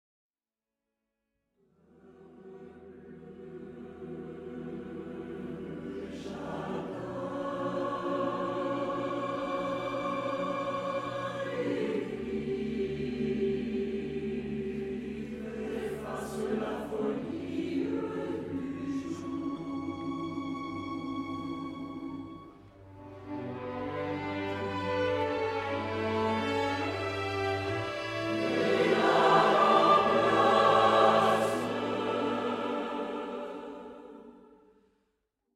musique nouvelle de chant choral
avec orchestre de chambre et a cappella
Les étudiantes et les étudiants du programme de composition et musique à l'image ainsi que leurs collègues étudiants du programme d'interprétation et création musicales vous promettent une expérience émouvante remplie d'harmonies riches et colorées sur des poèmes en français, anglais et latin dans la l’acoustique incomparable de l'Église St-Jean-Baptiste à Sherbrooke.